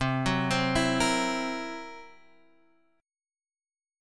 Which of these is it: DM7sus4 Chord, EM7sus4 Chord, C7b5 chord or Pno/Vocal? C7b5 chord